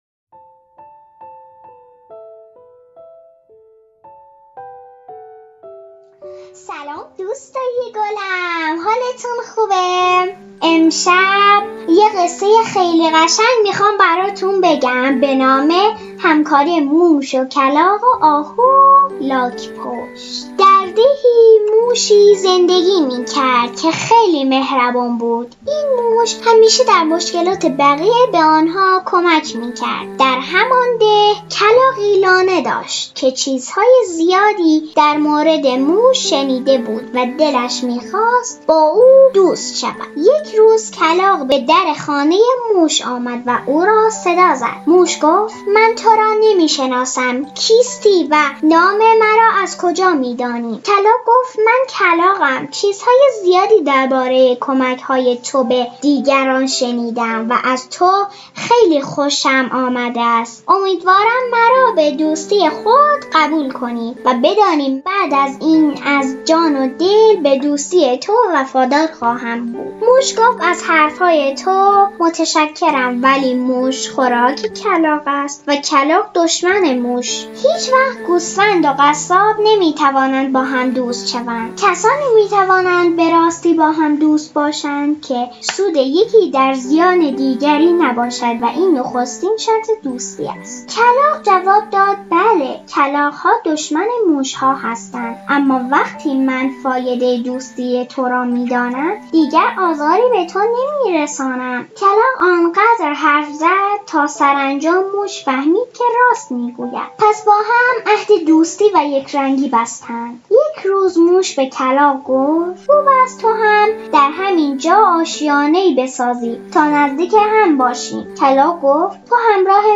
• قصه کودکان داستان کودکان قصه صوتی